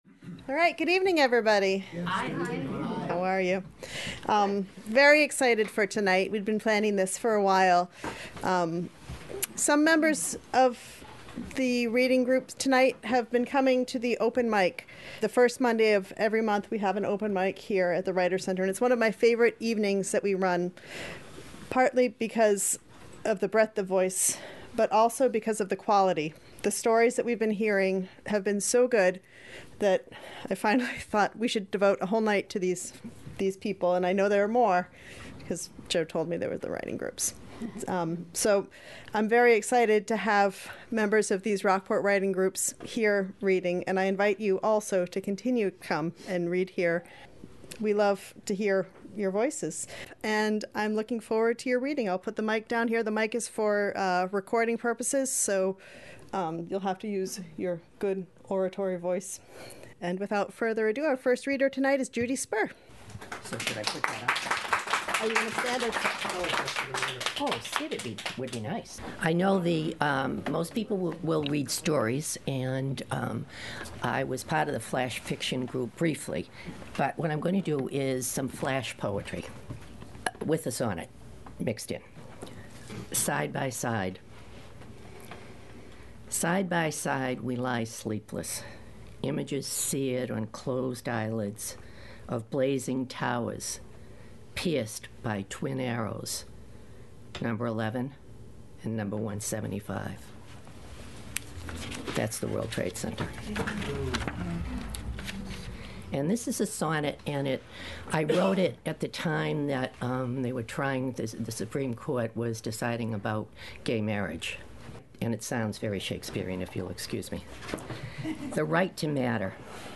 A Reading of Rockport Writing Groups - Gloucester Writers Center
The Gloucester Writers Center invites members of two long-standing Rockport writing groups to share their work.
a-reading-of-rockport-writing-groups.mp3